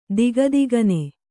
♪ digagigane